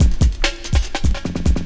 16 LOOP07 -R.wav